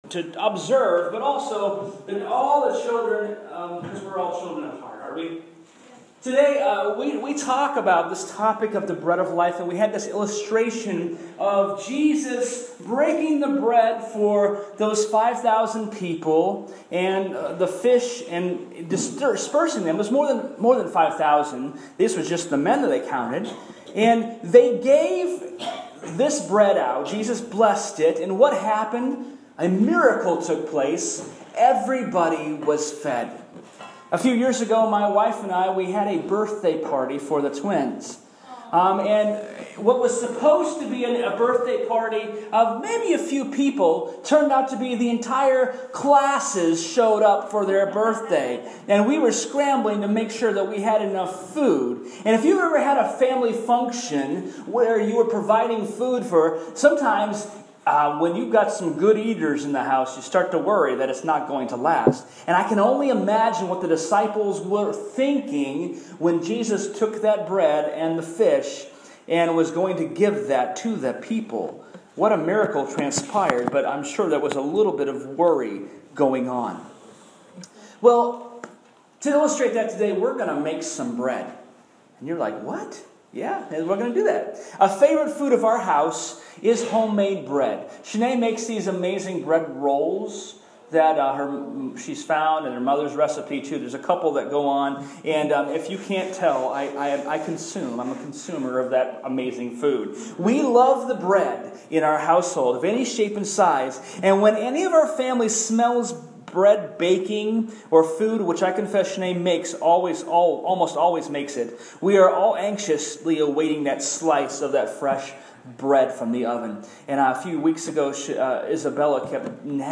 The Bread of Life Sermon Audio
bread-sermon-online-audio-converter-com.mp3